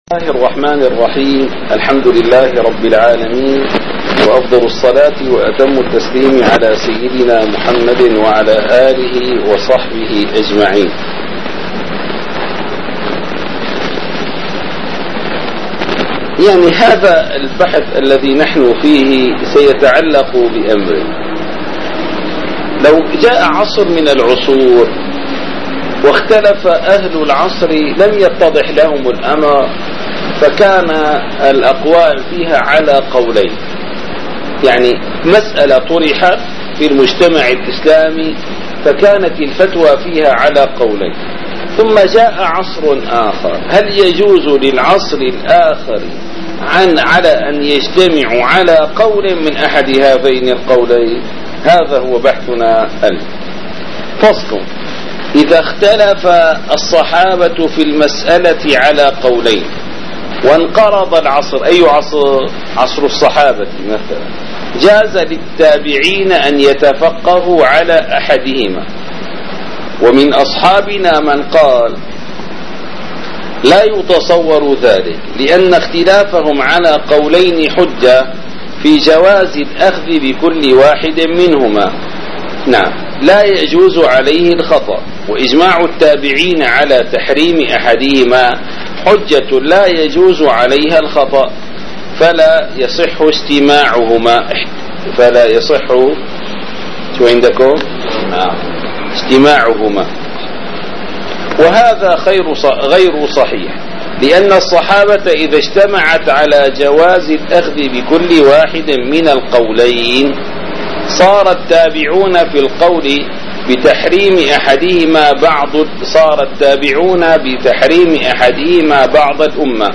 - الدروس العلمية - شرح كتاب اللمع للإمام الشيرازي - الدرس الأربعون: باب الإجماع بعد الخلاف (55)